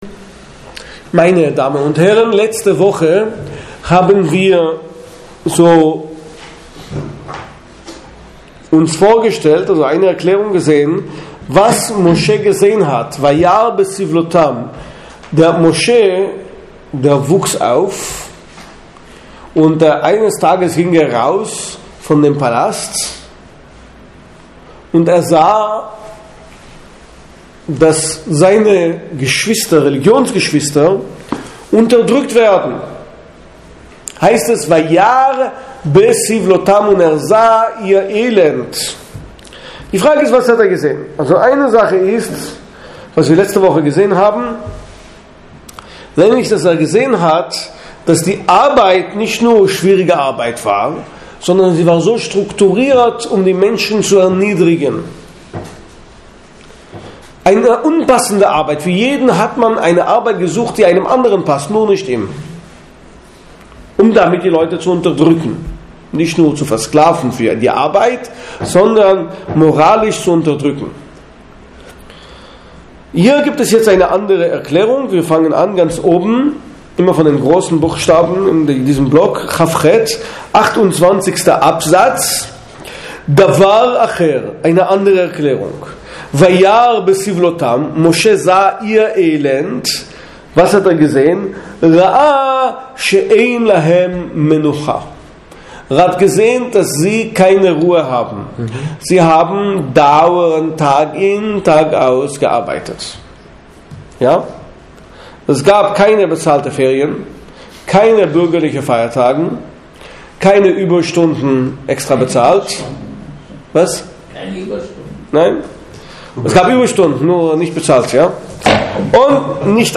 Die frühe Gestalt von Moses nach dem Midrasch #3 (Audio-Schiur)
Für welche Charakterzüge ist Moses bekannt, was ist bei ihm besonders ausgeprägt? P.S.: Dies ist der letzte Vortrag dieser Vortragsreihe.